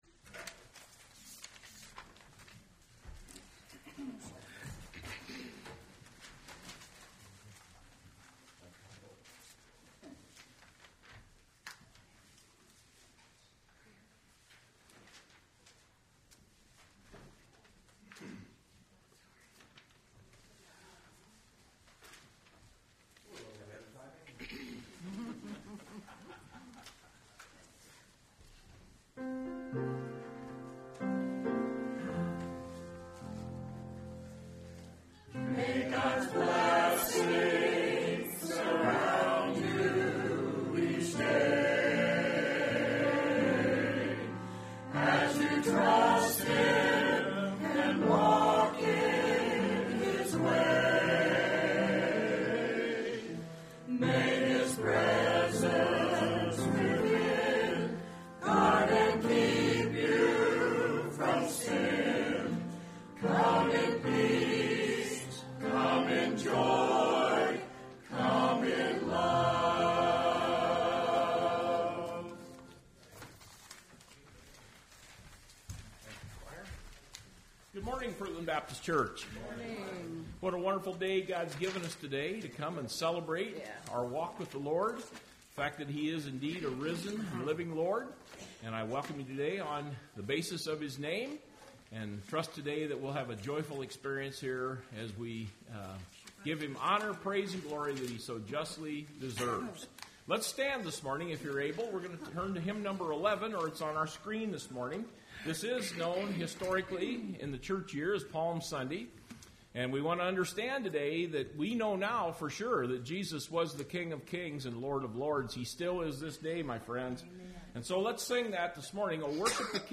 Service Type: Sunday Morning Service Topics: Cross , Easter , Resurrection , Resurrection Day « More Proofs That Jesus Is God!